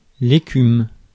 Ääntäminen
IPA: [e.kym]